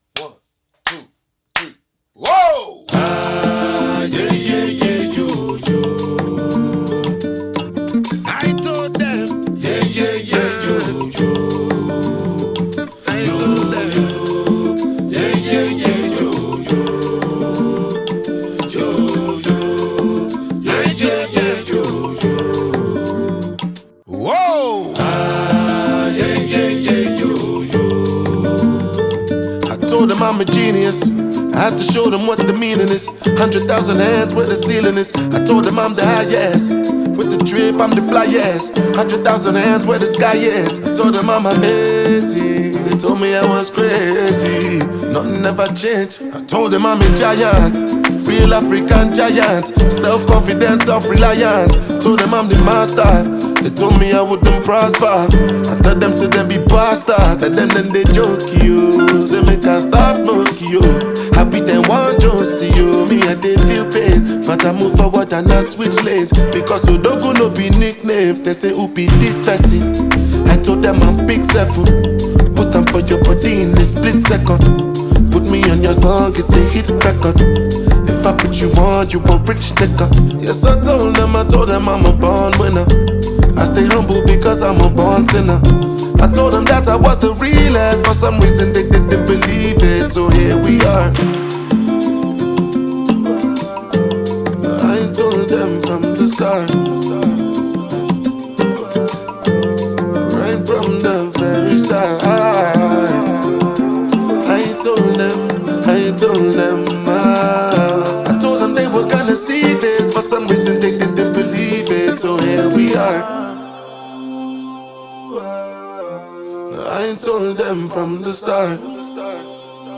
Afro beats